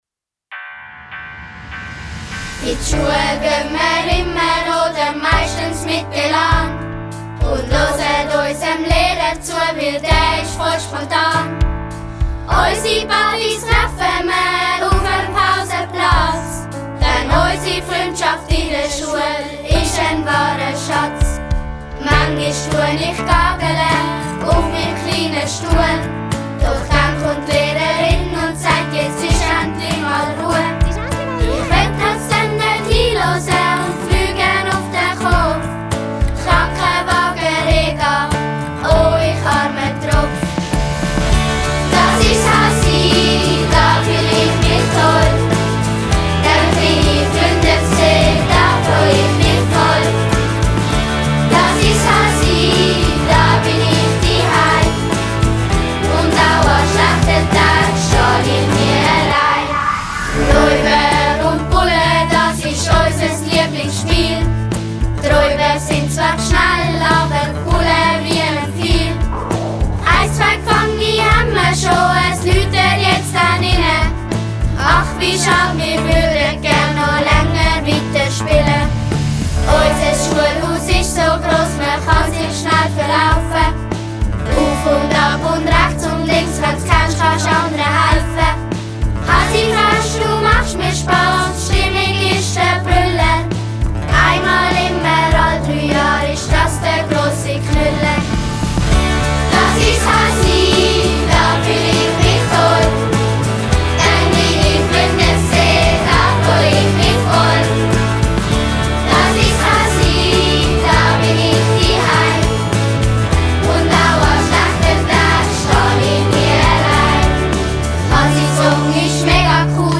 Ende November 2017 wurde dann der Song professionell aufgenommen und von allen Schülerinnen und Schüler eingesungen...